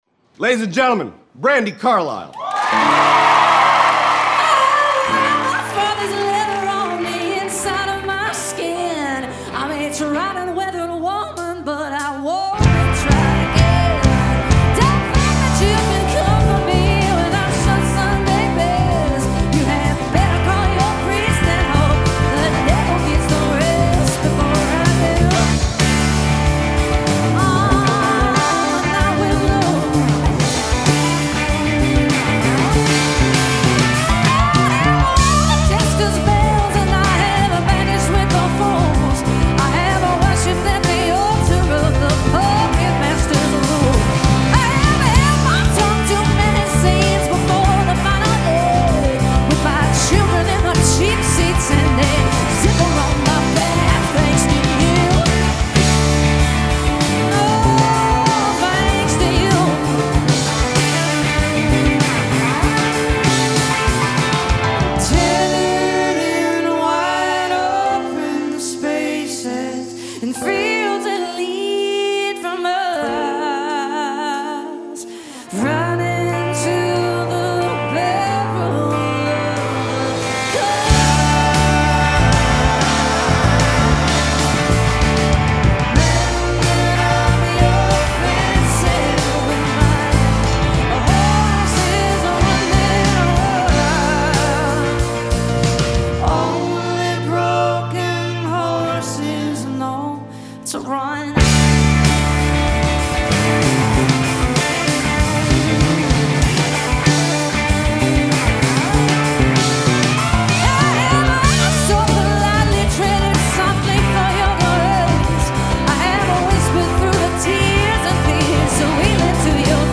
and impeccable vibrato